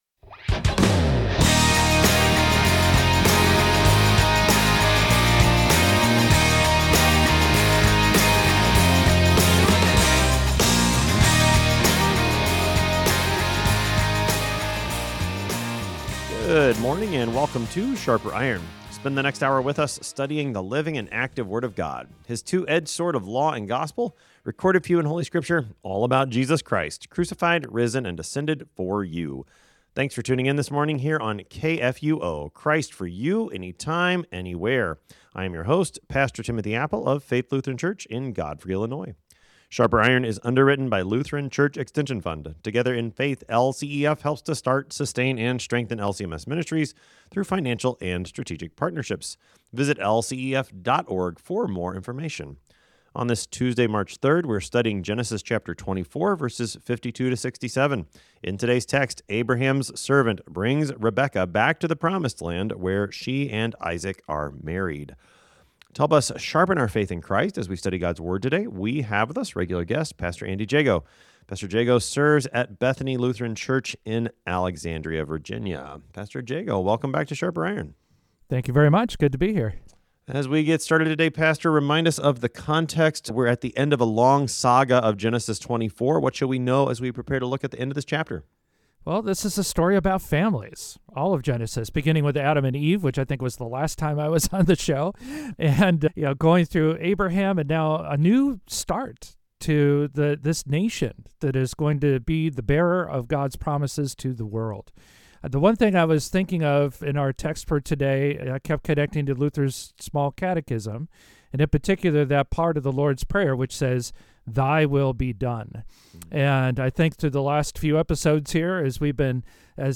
Two pastors engage with God's Word to sharpen not only their own faith and knowledge, but the faith and knowledge of all who listen.